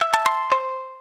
shamisen_eac1c.ogg